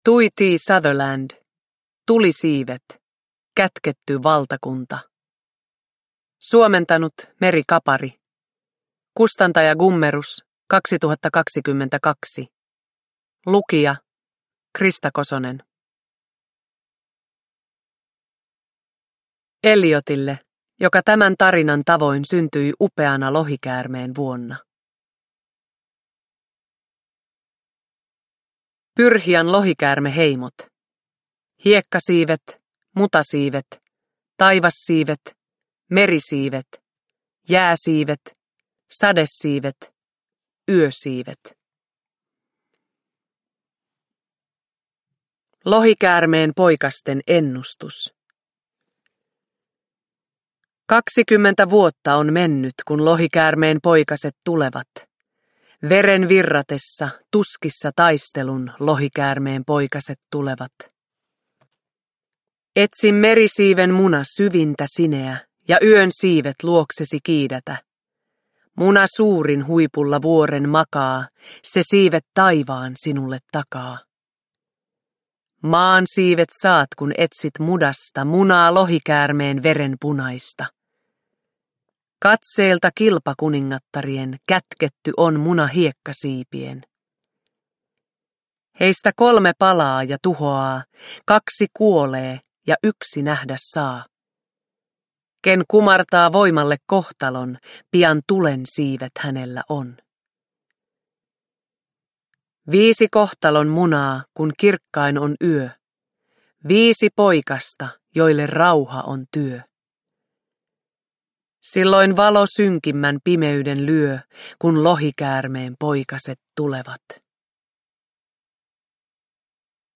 Kätketty valtakunta – Ljudbok
Uppläsare: Krista Kosonen